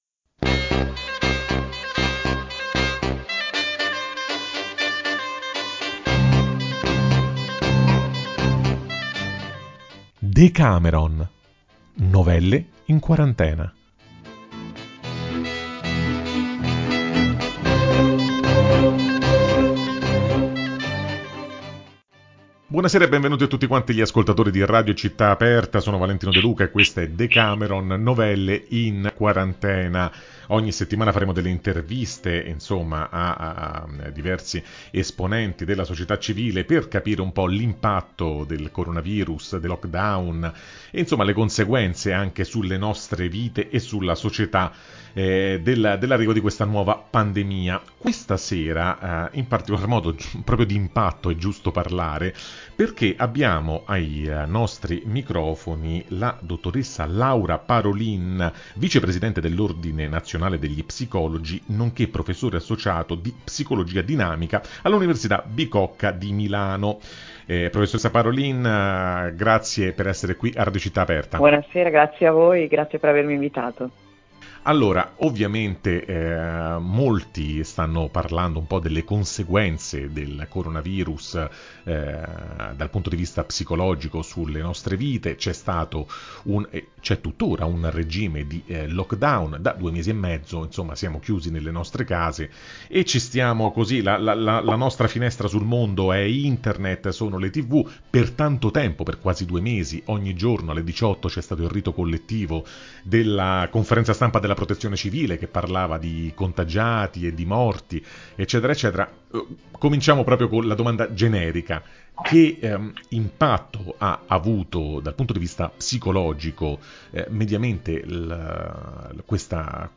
Non andrà tutto bene. Il disagio mentale durante il lockdown in Italia. [Intervista